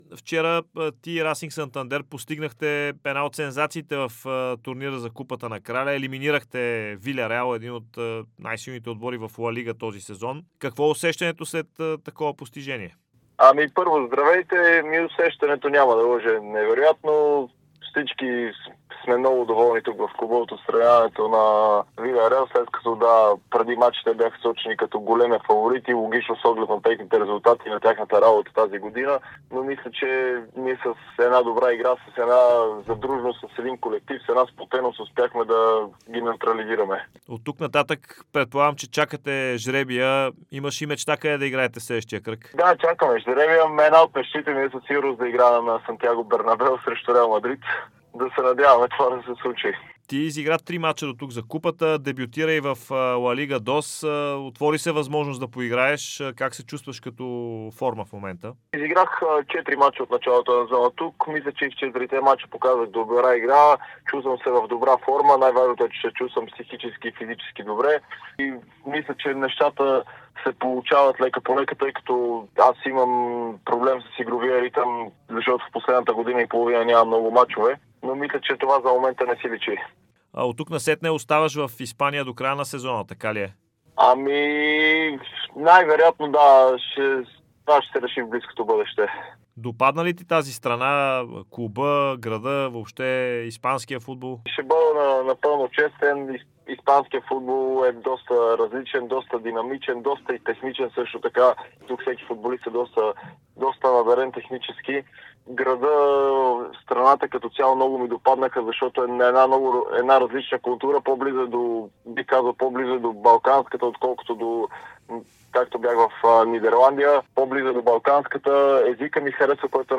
Вратарят Пламен Андреев говори пред Дарик и dsport, след като неговият Расинг отстрани Виляреал (2:1) за Купата на краля. Бившият страж на Левски обсъди адаптацията си в Испания, чувствата след елиминацията на „жълтата подводница“ и коя е мечтата му.